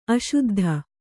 ♪ aśuddha